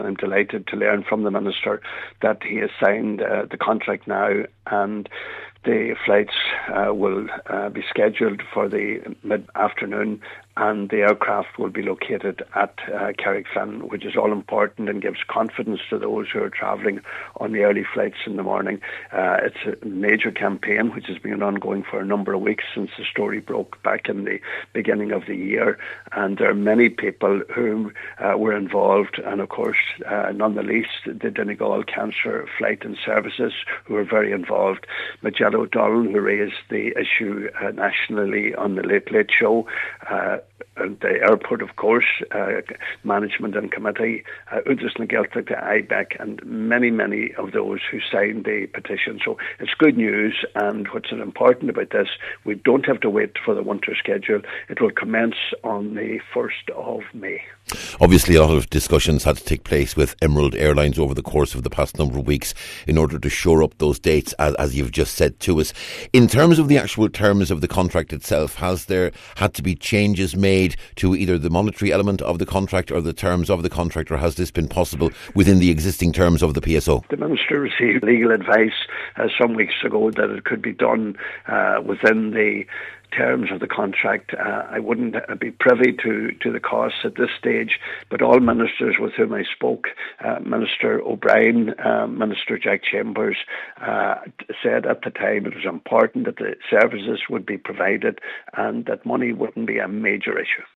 Deputy Pat the Cope Gallagher spoke with Minister O’Brien this morning, he says this is the news people in West Donegal and further afield have been waiting for………